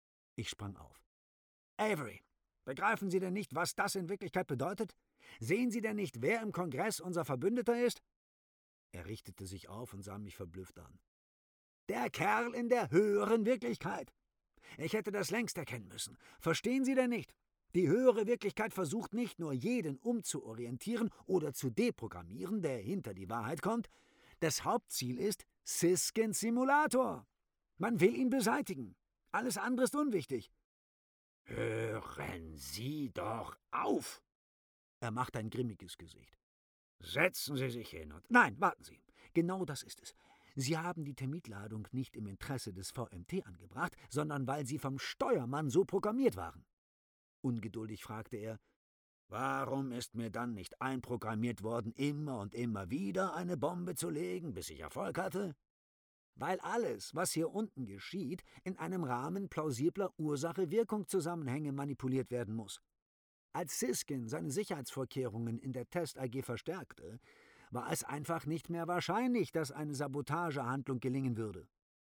Sprecherdemos
Hörbuch: Die Kunst des Erzählers
Auszug-Hoerbuch.mp3